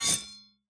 Pick Up Metal A.wav